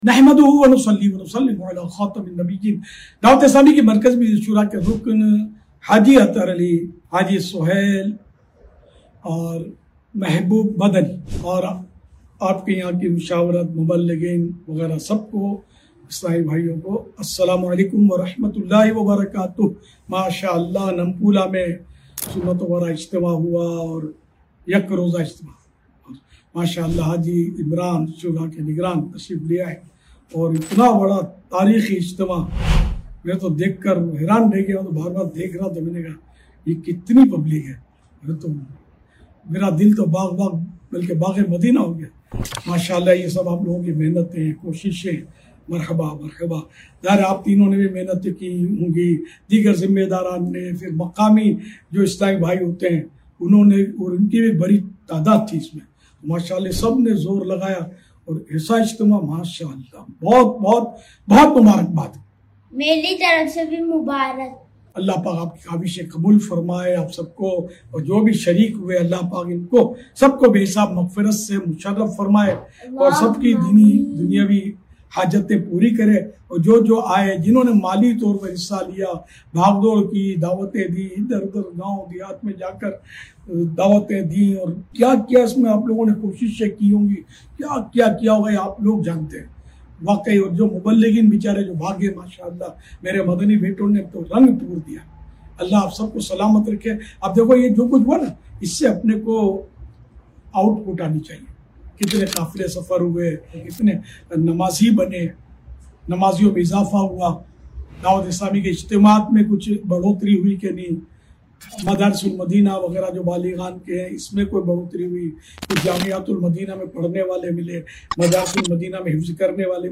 تاریخی اجتماع - موزمبیق افریقہ